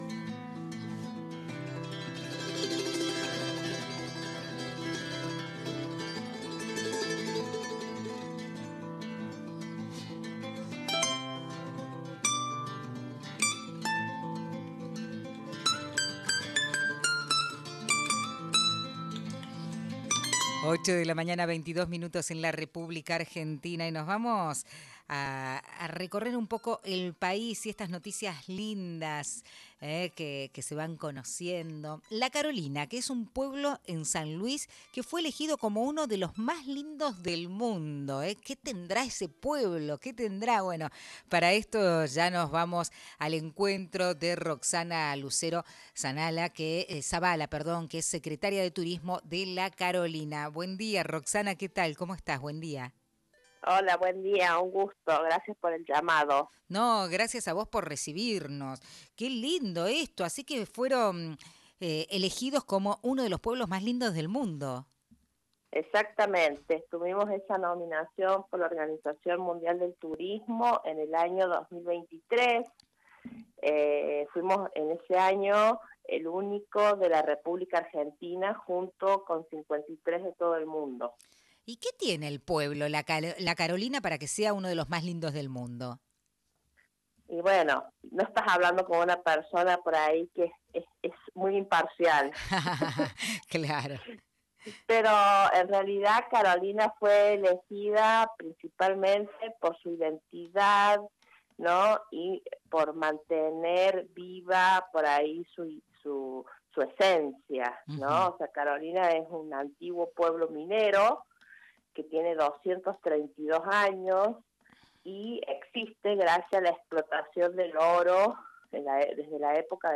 entrevistó a Roxana Lucero Zavala, Secretaria de Turismo de la Carolina